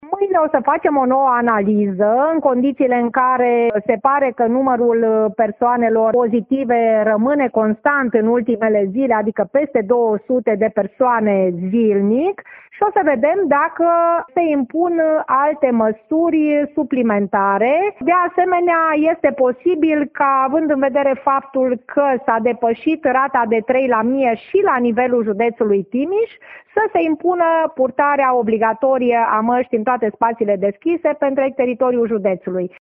Prefectul județului Timiș, Liliana Oneț, a mai declarat că în cursul zilei de mâine se va decide dacă, la nivelul județului Timiș, se impun și alte măsuri, cum ar fi purtarea mastii in aer liber, pe toata raza judetului: